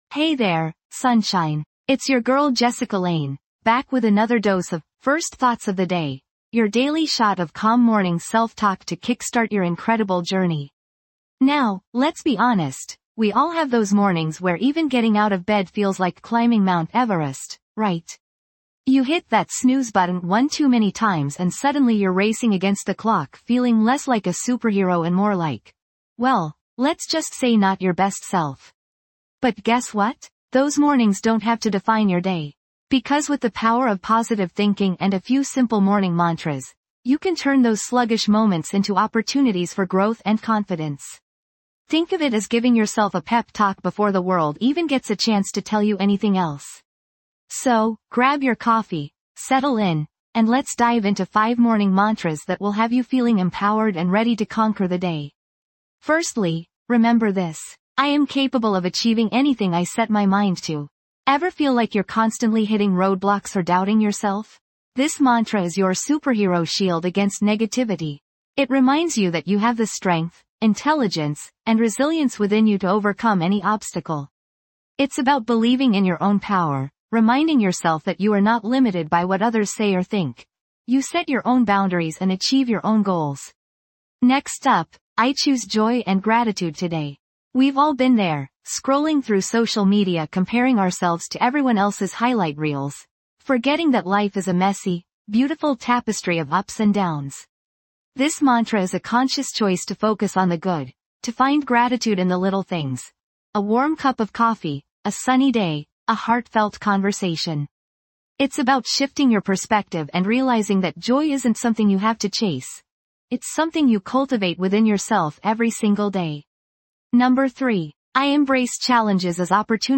"First Thoughts of the Day | Calm Morning Self Talk" guides you through mindful moments each morning, helping you cultivate inner peace and set a positive tone for the day ahead. Through gentle self-talk exercises and calming audio, we'll explore gratitude, acceptance, and positive affirmations, fostering a sense of calm amidst the daily hustle.